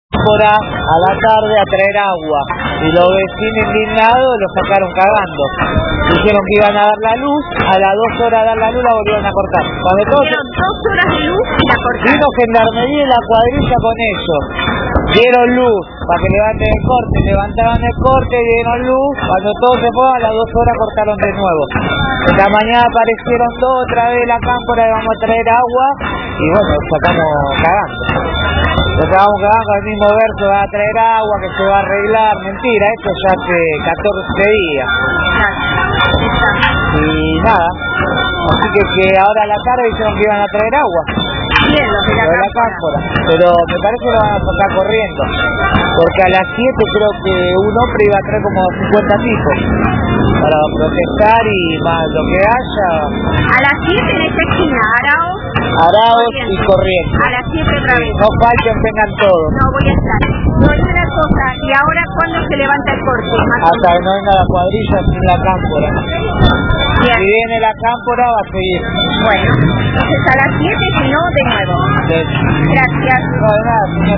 Mientras realizamos esta nota, el corte continúa, los bocinazos son permanentes y el tráfico es insoportable, por la noche por cualquier ventana se puede ver el apagón que domina Villa Crespo, el área es enorme, sólo los negocios que cuentan con un grupo electrógeno pueden continuar con su trabajo y no tener que tirar la comida que requiere frío.